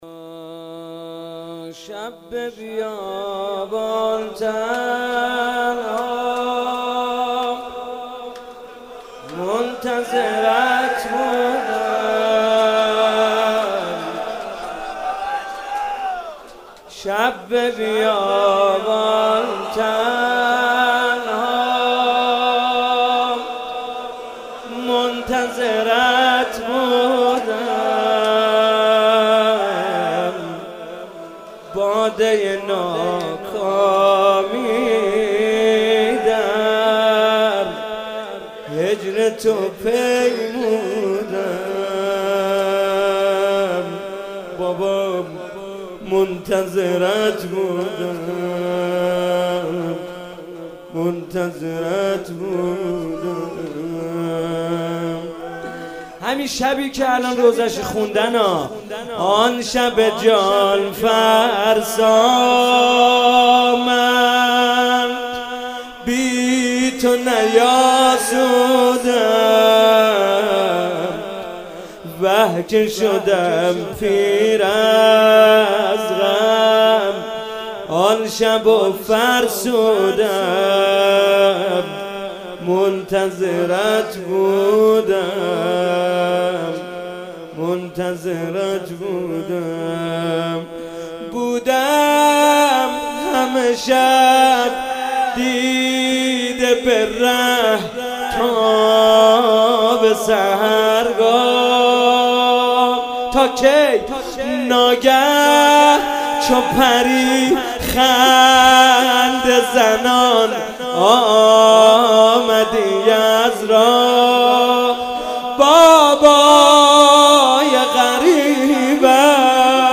روضه
مناسبت : شب سوم رمضان